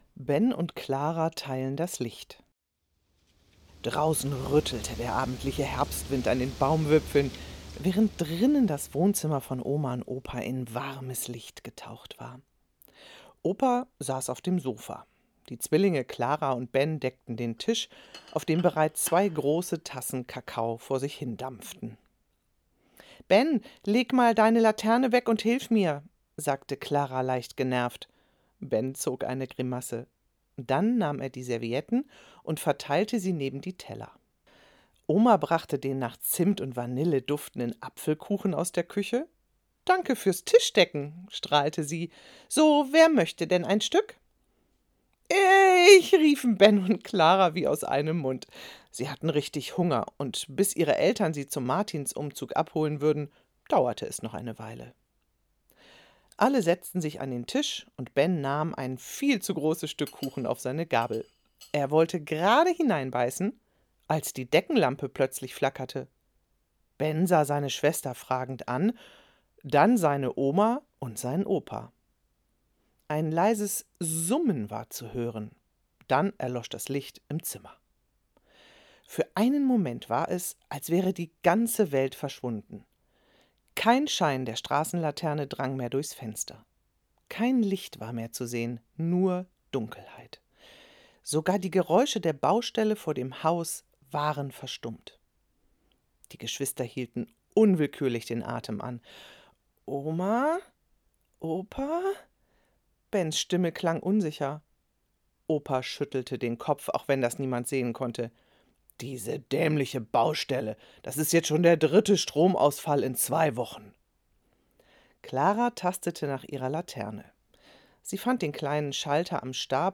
Die Vorlesegeschichte zum Martinsfest 2025 als Hörversion (Dauer ca. 10 min).
2025_SanktMartin_Vorlesegeschichte_audio.mp3